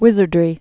(wĭzər-drē)